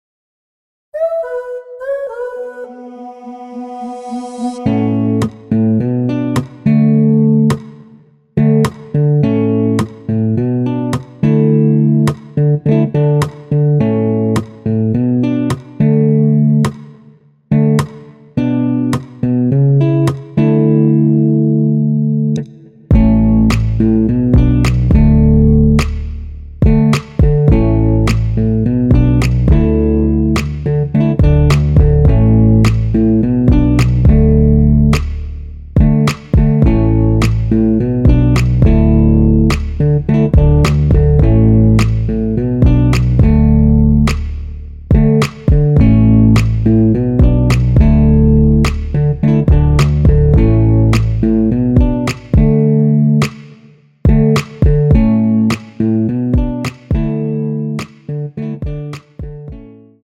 원키에서(-2)내린 MR입니다.
앞부분30초, 뒷부분30초씩 편집해서 올려 드리고 있습니다.
중간에 음이 끈어지고 다시 나오는 이유는